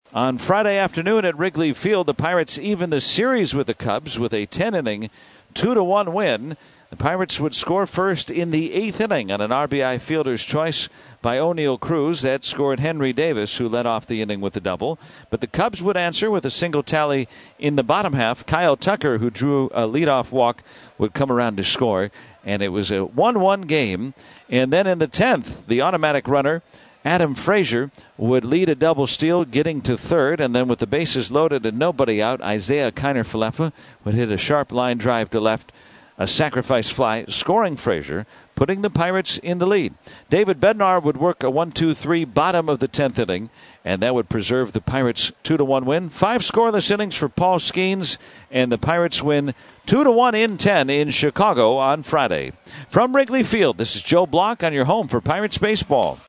6-13-recap.mp3